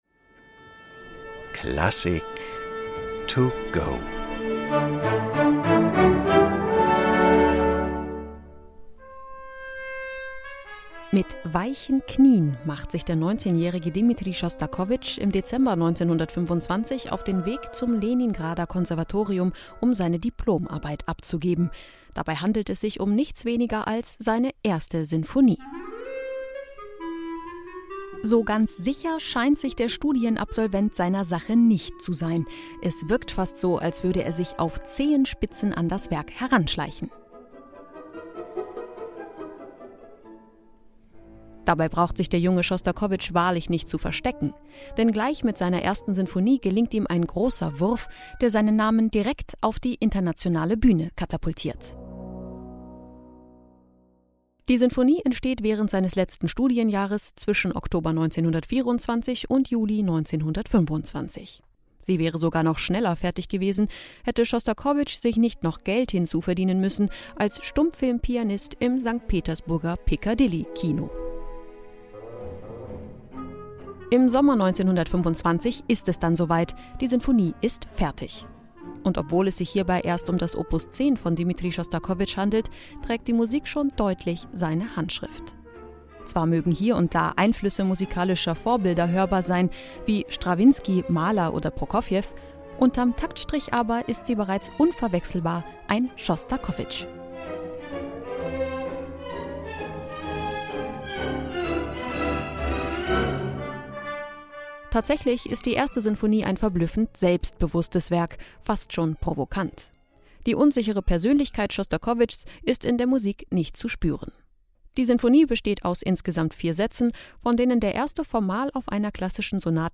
"Klassik to Go" - die digitale Werkeinführung zum Download!